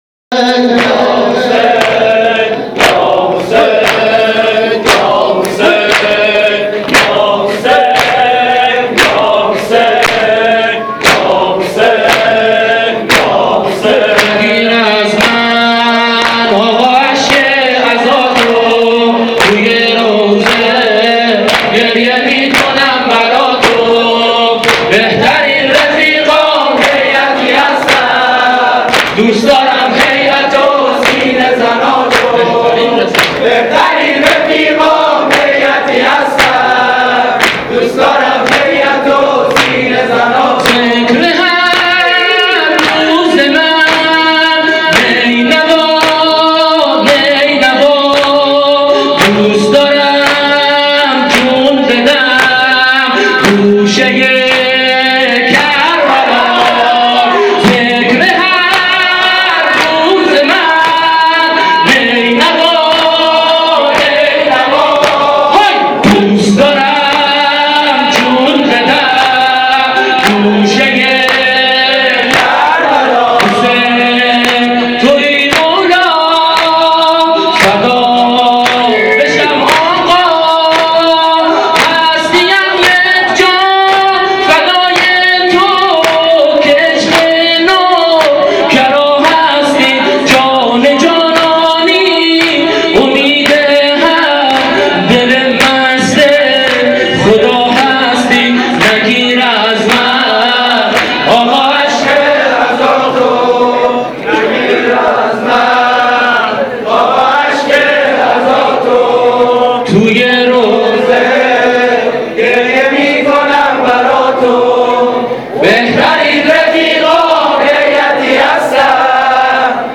مداحی
و سبک شور حضرت امام حسین